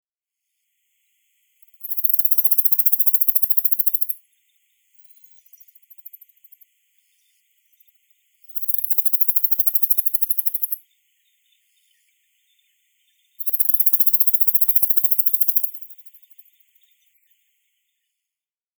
スピーカー画像をクリックすると鳴き声が聞けます。
ヒメギス